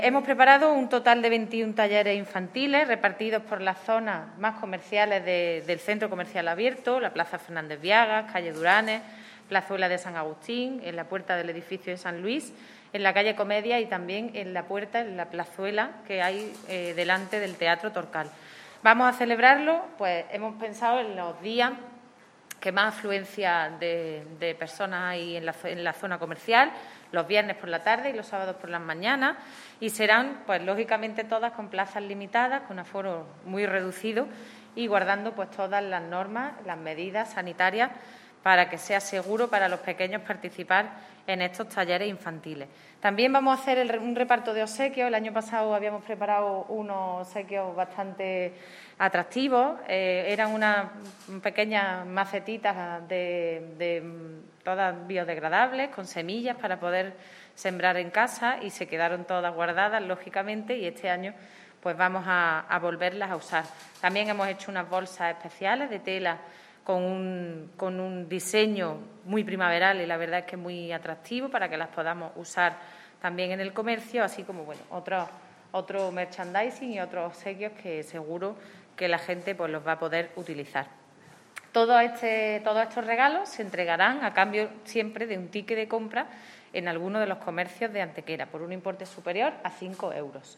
La teniente de alcalde delegada de Turismo, Patrimonio Histórico, Políticas de Empleo y Comercio, Ana Cebrián, ha presentado hoy en rueda de prensa la Campaña de Primavera con la que se trata de impulsar, desde el Ayuntamiento, el comercio de nuestra ciudad durante las próximas semanas coincidiendo con el inicio y transcurso de la nueva estación.
Cortes de voz